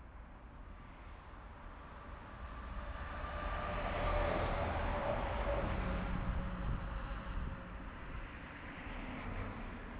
noise.wav